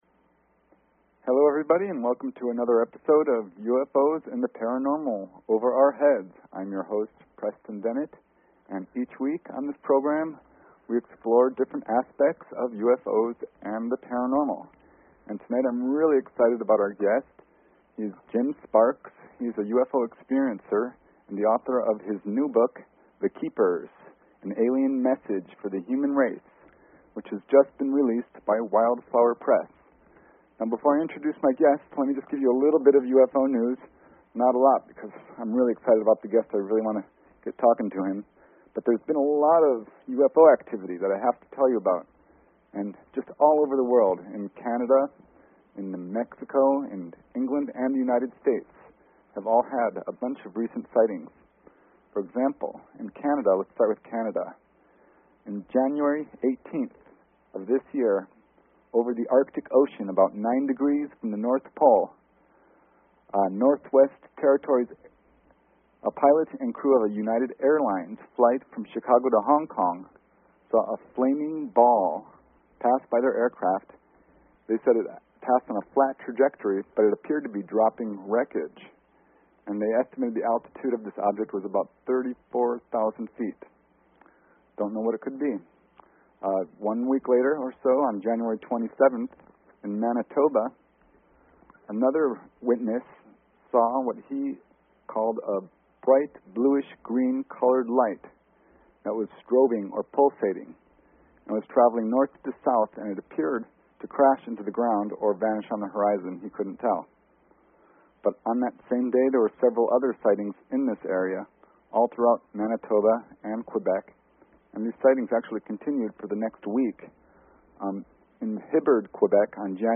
Talk Show Episode, Audio Podcast, UFOs_and_the_Paranormal and Courtesy of BBS Radio on , show guests , about , categorized as